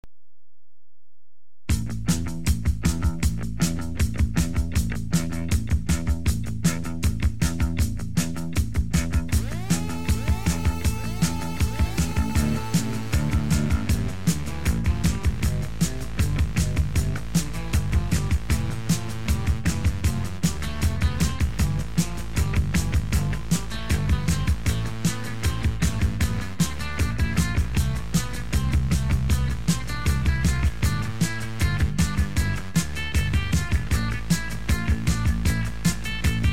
気に入ったドラムの音色